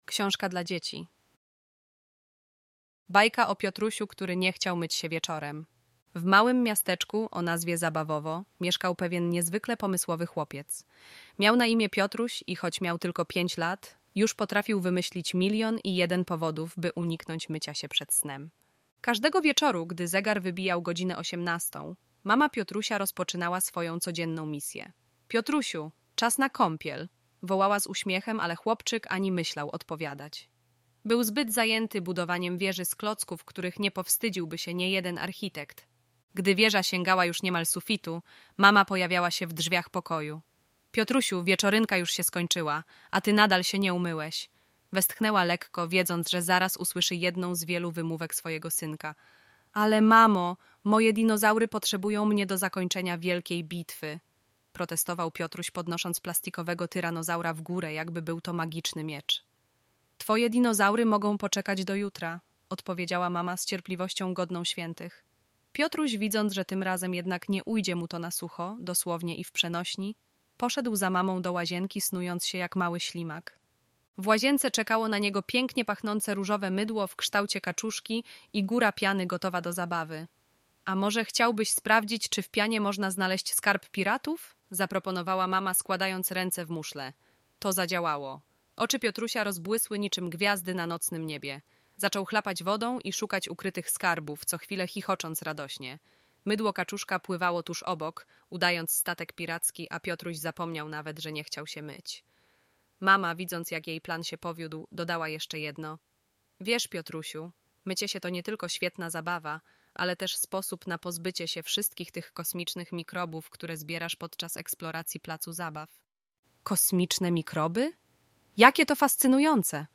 🎧 Wygodna forma – Audiobook MP3